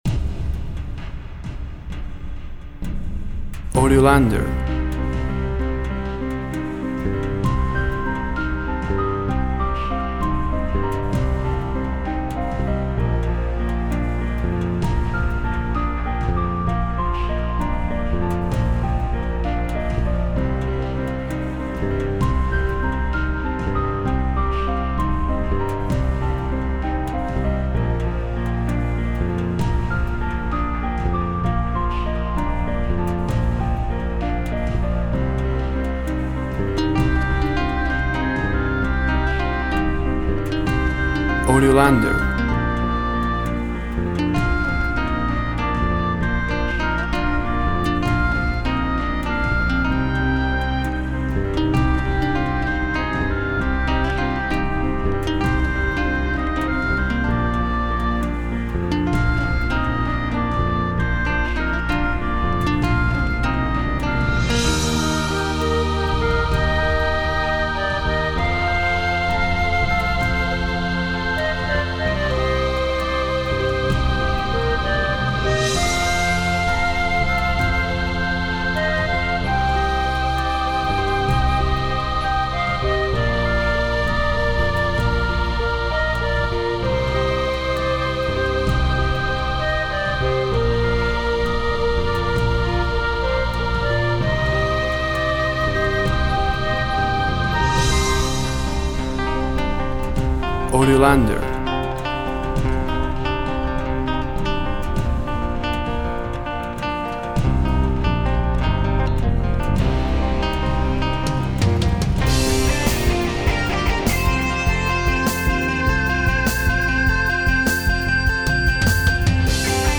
Tempo (BPM) 60/75